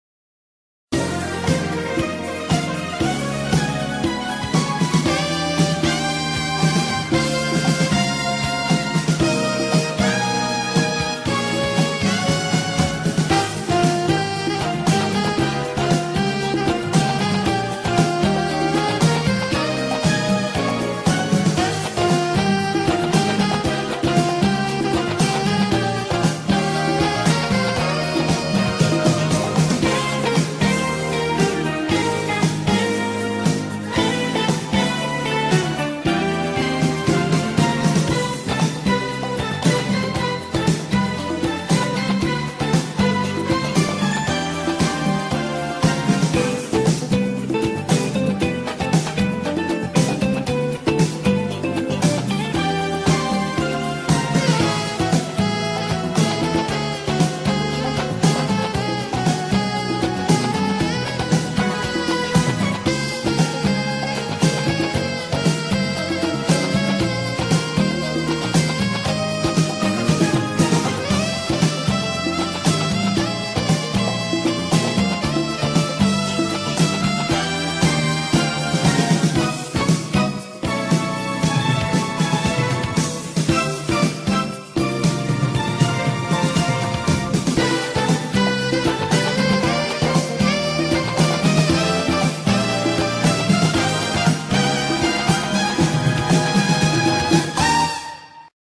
BPM108-118
Audio QualityPerfect (Low Quality)